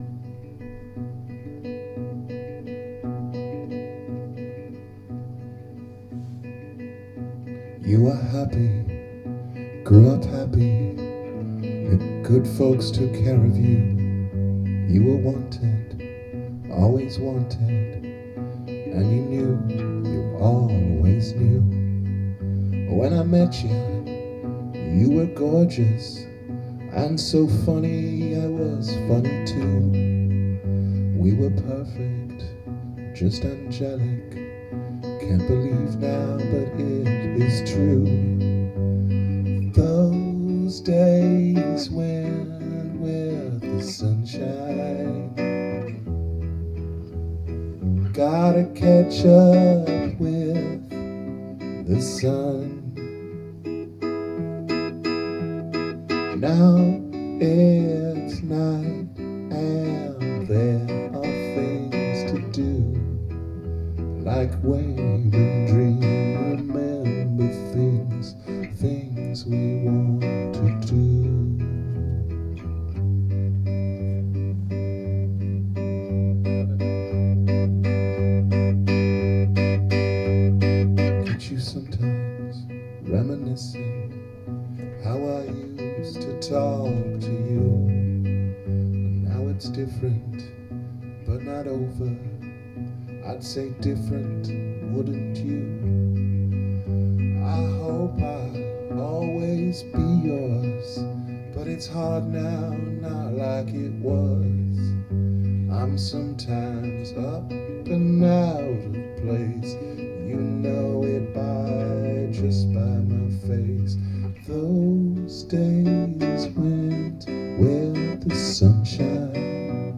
Days of Sunshine – rough sketch rehearsal 9.2.2012
it’s boomy, lo-fi (not in the good way) and incomplete, but what we’ve came up with so far.